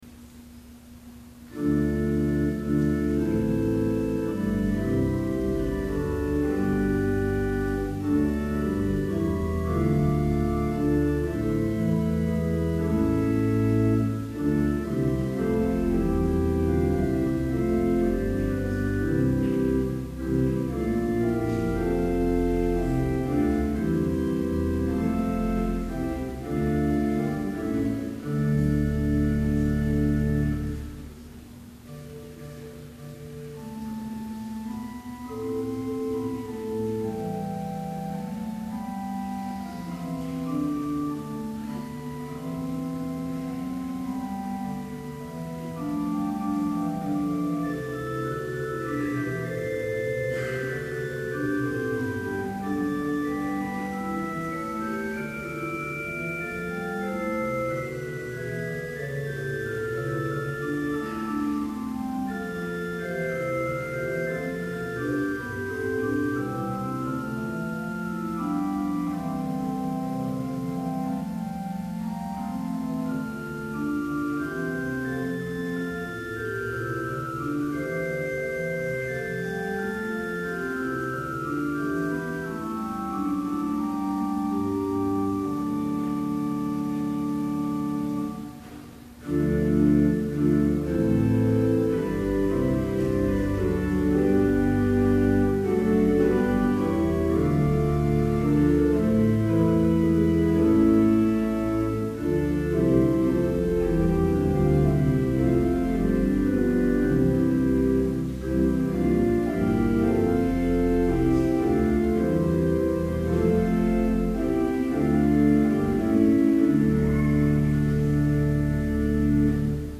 Complete service audio for Summer Chapel - June 1, 2011